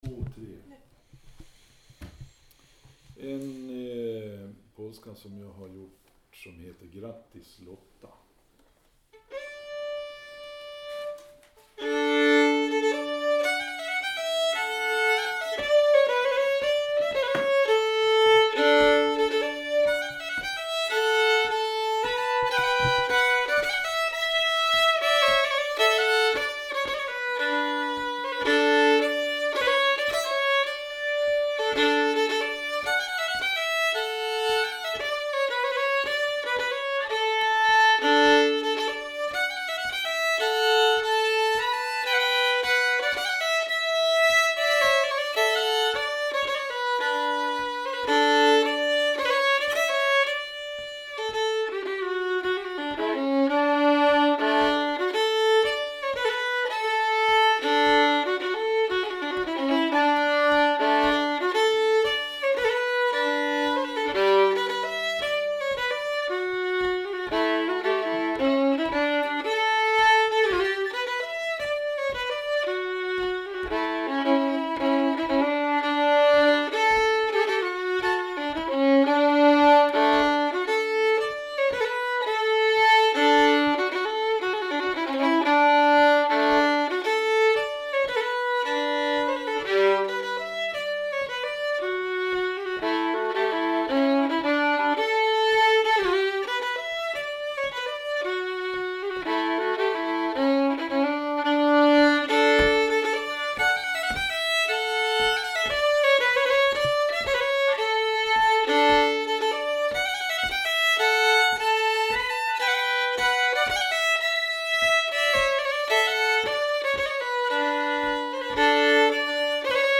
Här är låtarna från kursen med Per Gudmundsson den 24 augusti 2019
Grattis Lotta, polska av Per Gudmundsson